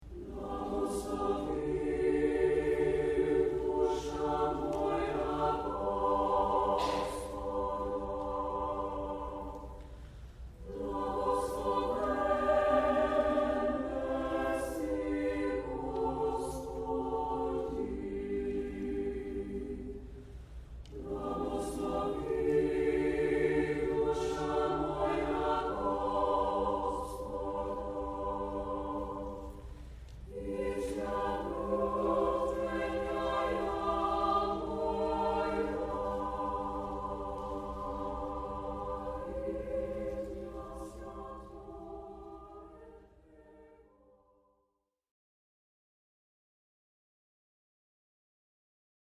Voicing: satb Accompaniment: a cappella Language